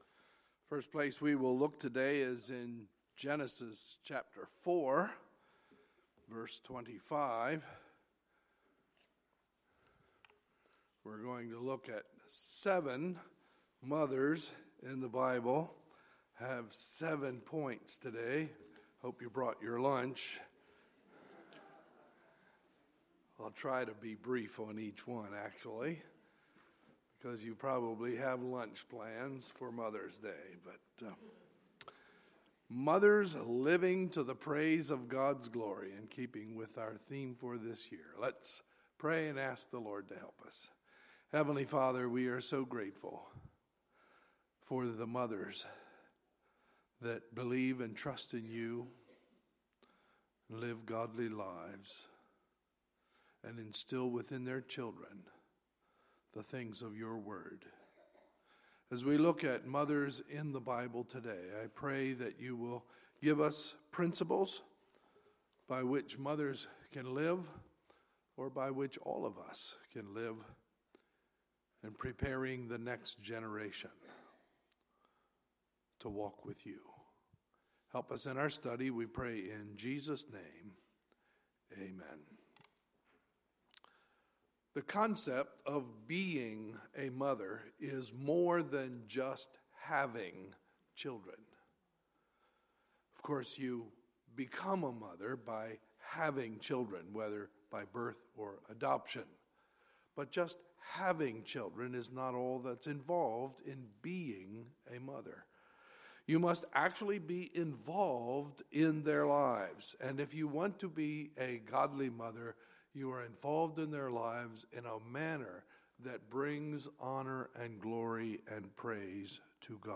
Sunday, May 8, 2016 – Sunday Morning Service